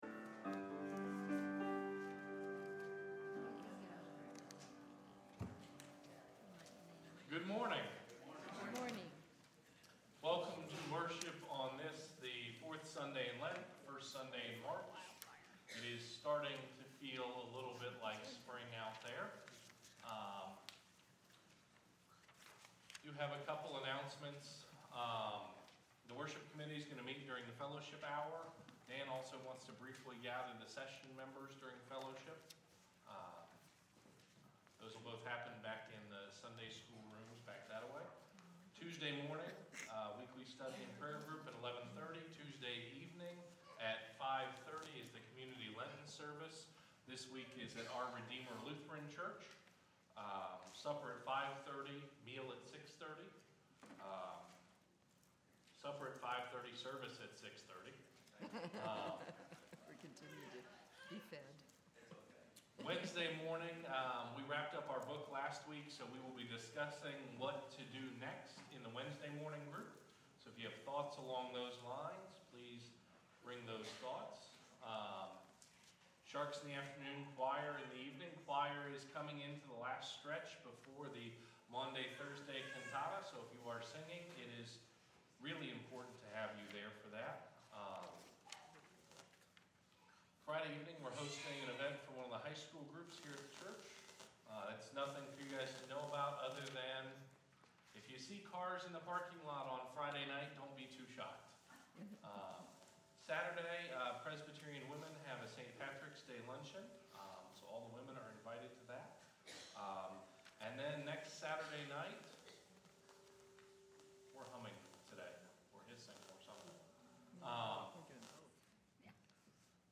Worship from March 6, 2016